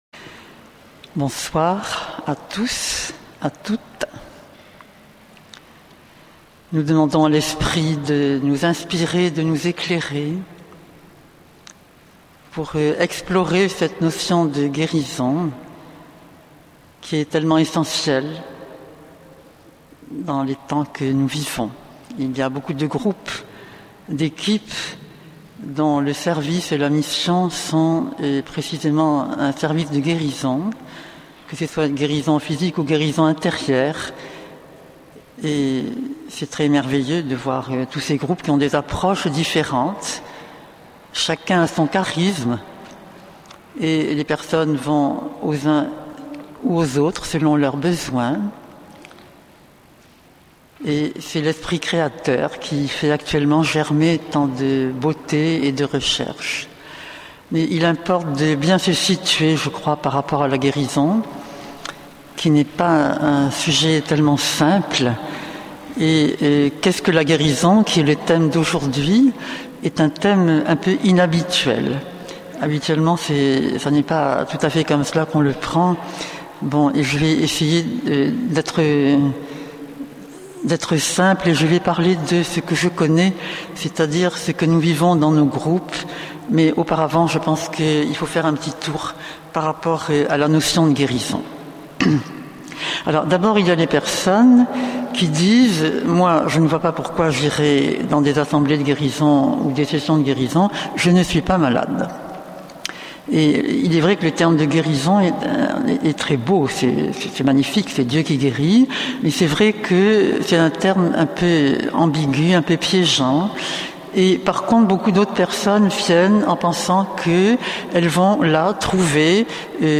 Format :MP3 64Kbps Mono